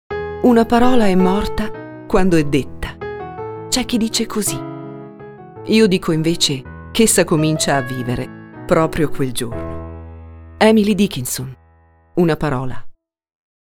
Voce per la poesia
recita “Una parola”, la poesia di Emily Dickinson